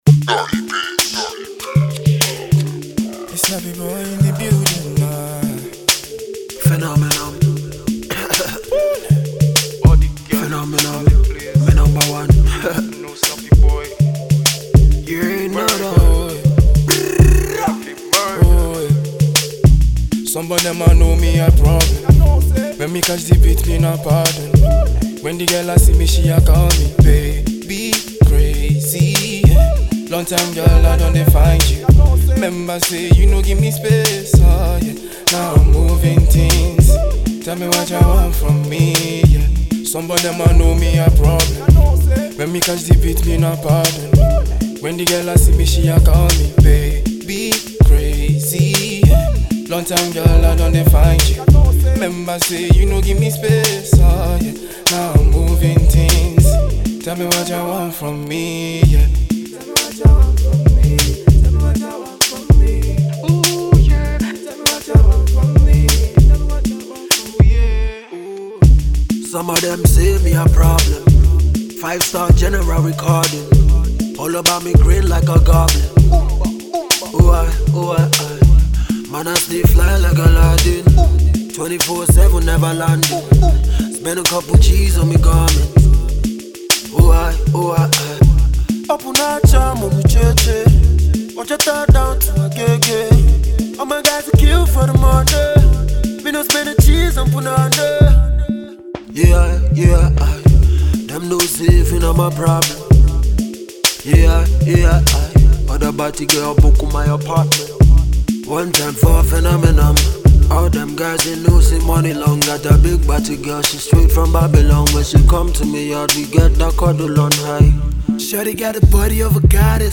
patua infused record